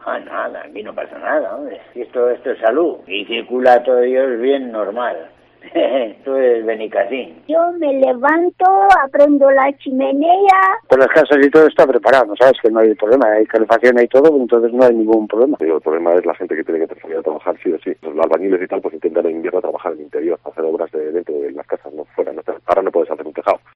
En plena ola de frío de Navidad, los vecinos de Valderredible responden con ironía y con humor ante las temperaturas extremas que han vivido esta semana